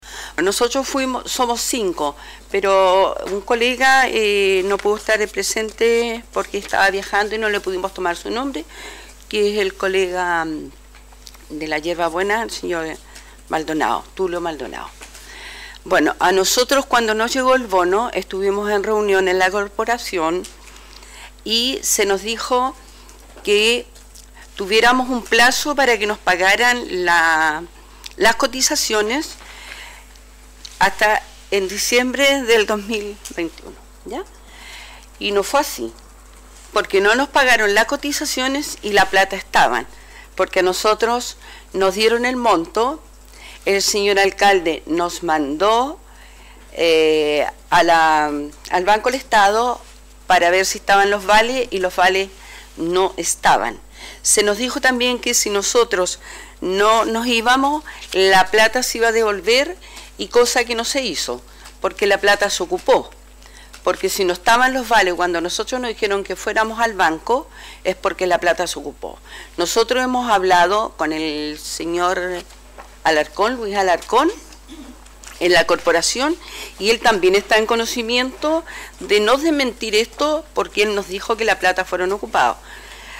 se dirigió al concejo municipal